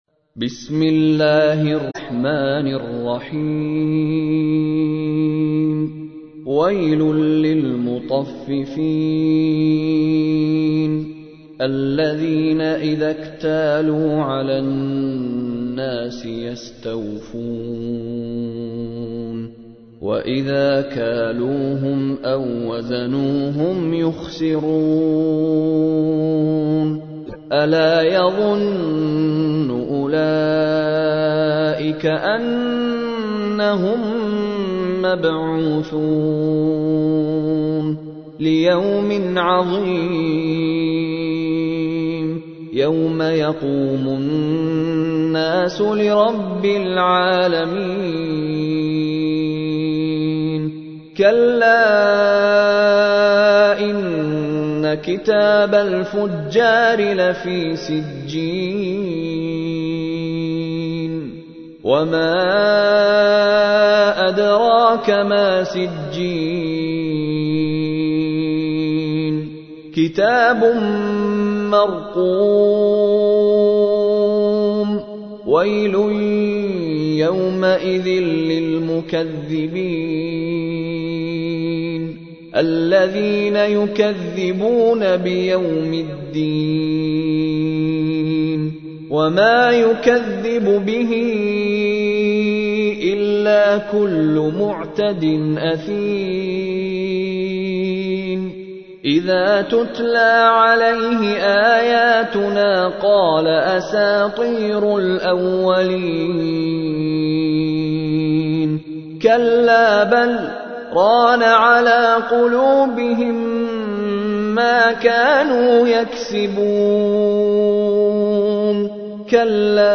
تحميل : 83. سورة المطففين / القارئ مشاري راشد العفاسي / القرآن الكريم / موقع يا حسين